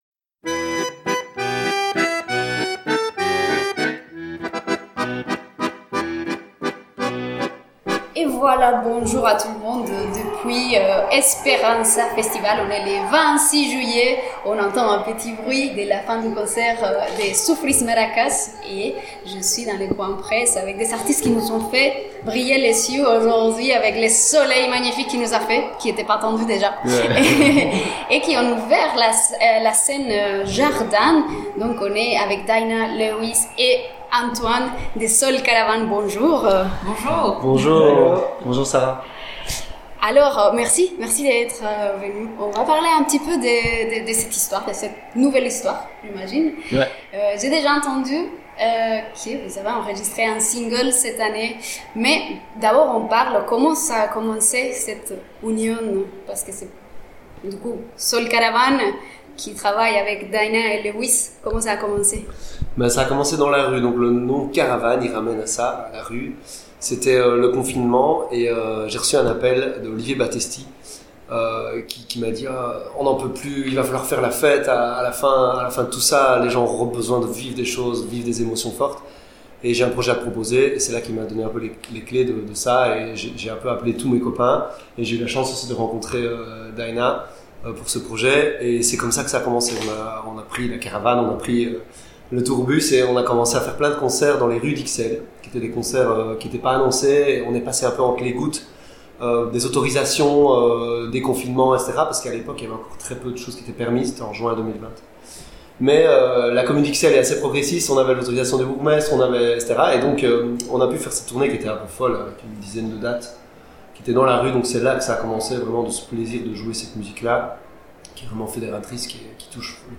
Interview
Abbaye de Floreffe 25·26·27 juillet 2025 Village des possibles Radio Radio Esperanzah! - 106.2 FM